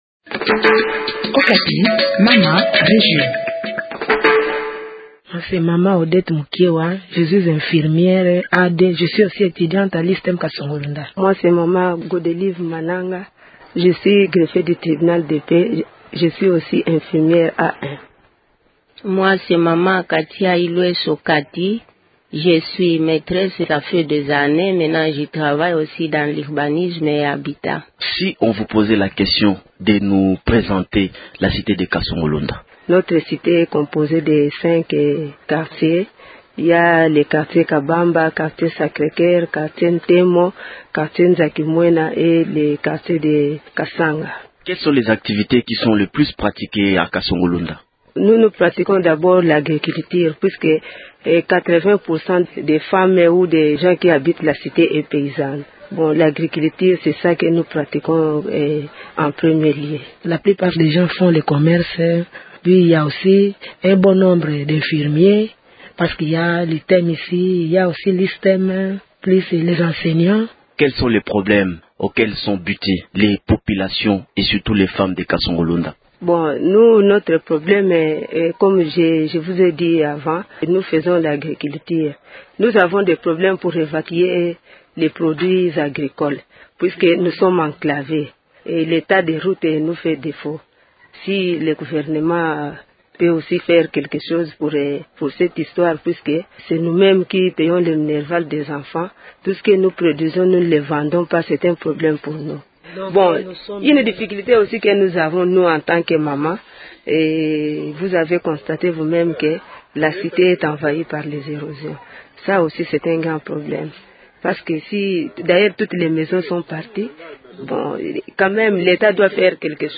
en marge du Festival international des arts contemporains de Kasongo Lunda.